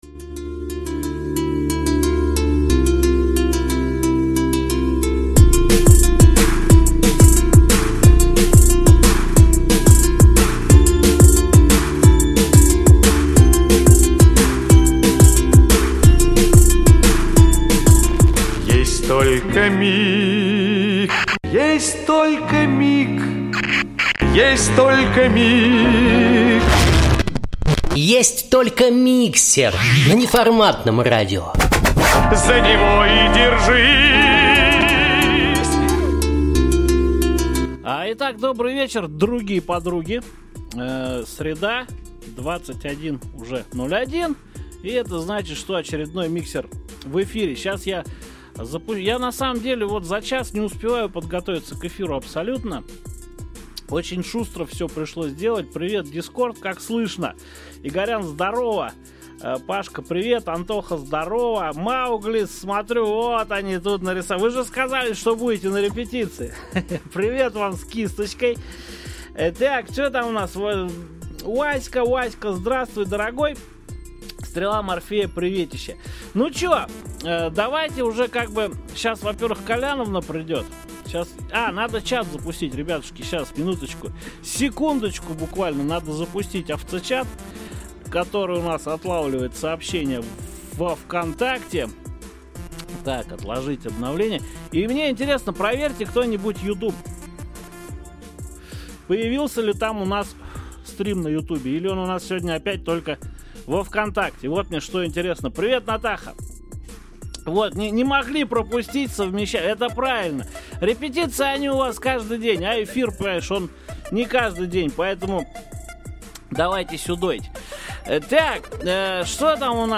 Эфир, как и планировали, получился достаточно шумным.